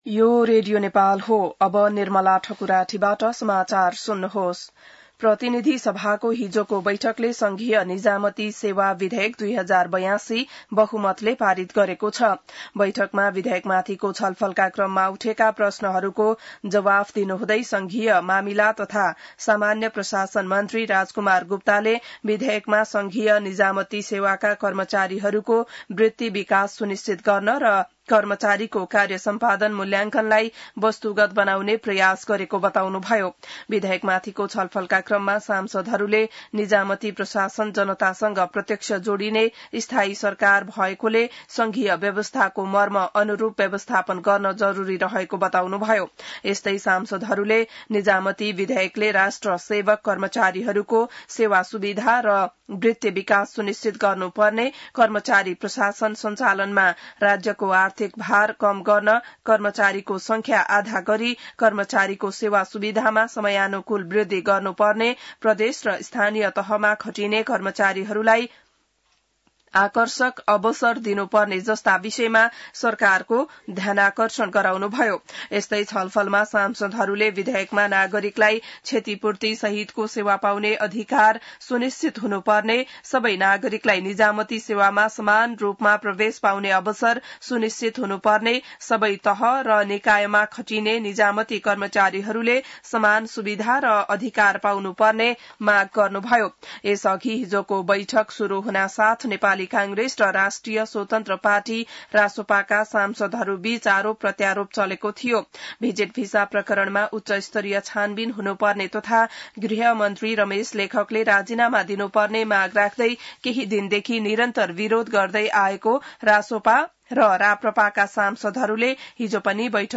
An online outlet of Nepal's national radio broadcaster
बिहान ६ बजेको नेपाली समाचार : १६ असार , २०८२